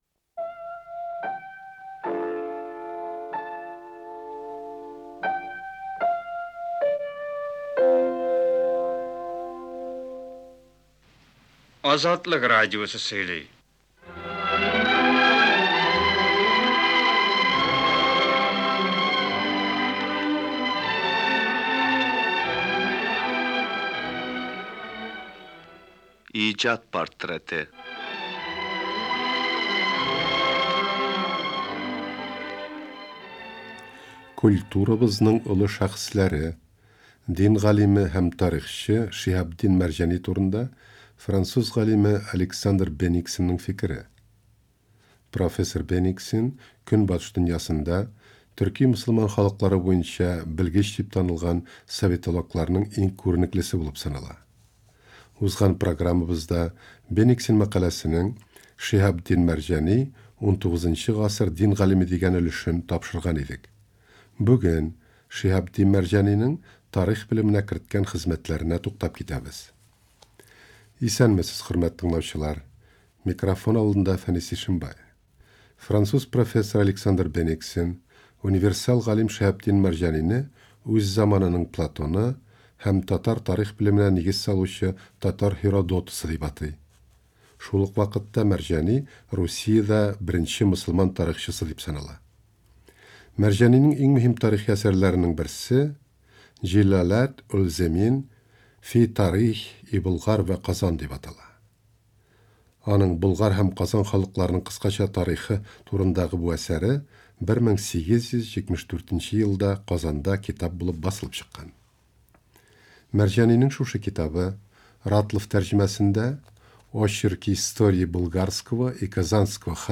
Күренекле татар галиме, дин әһеле Шиһабетдин Мәрҗани турында Азатлык эфирында яңгыраган тагын бер тапшыру тәкъдим итәбез.